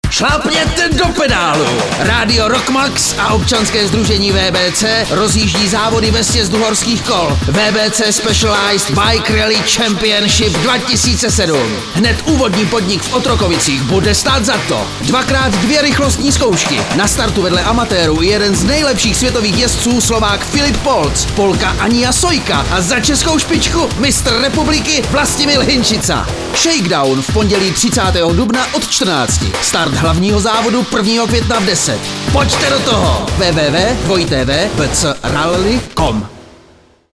Upoutávka na závod - Rock Max (*.mp3, 0,5 MB, 0:37)
radiospot.mp3